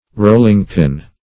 Rolling-pin \Roll"ing-pin`\, n.